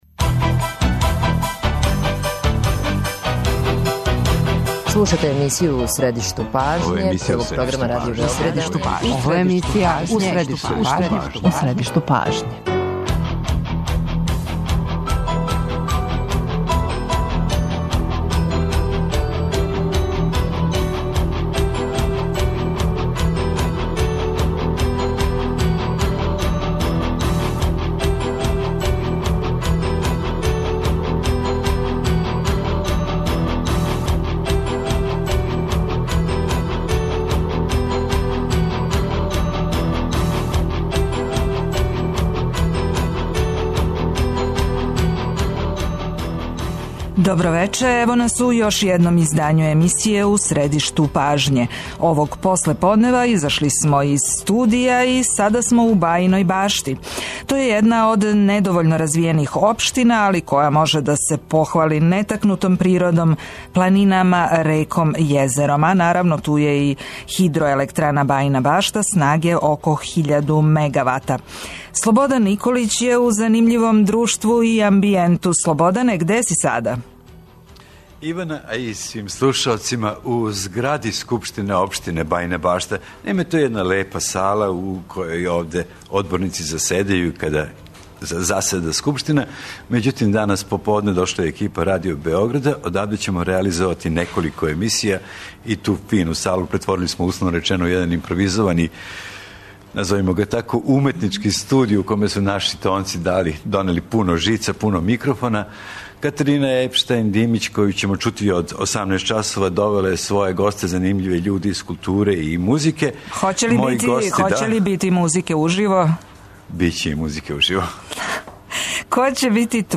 Емисију реализујемо из Бајине Баште, једне од недовољно развијених општина Србије, која може да се похвали недирнутом природом, лепотом планина, шума, река и језера, гостопримством људи...
Бајина Башта очекује да ће развоју допринети и туризам, занатска производња, производња здраве хране и прекогранична сарадња. О томе ћемо разговарати с привредницима Бајине Баште, као и с председником општине Златаном Јовановићем.